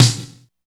85 DEEP SNR.wav